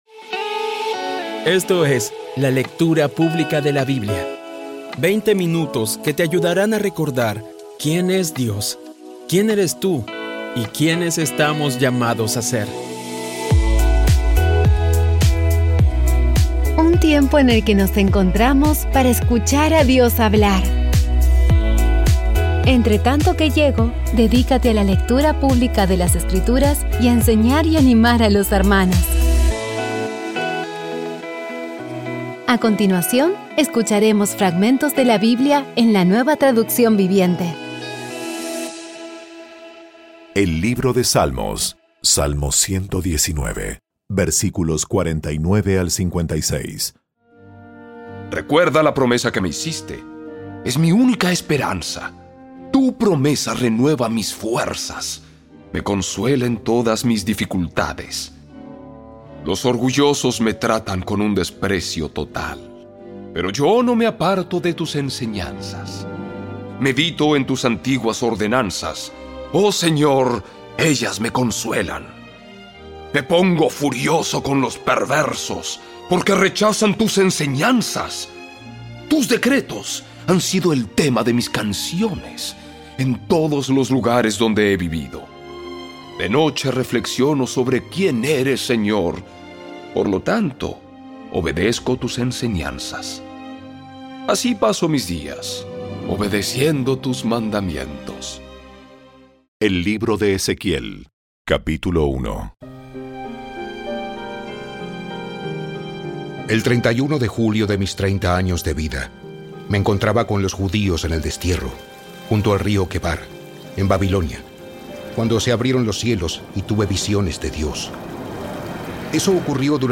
Audio Biblia Dramatizada Episodio 302
Poco a poco y con las maravillosas voces actuadas de los protagonistas vas degustando las palabras de esa guía que Dios nos dio.